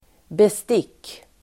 Ladda ner uttalet
Uttal: [best'ik:]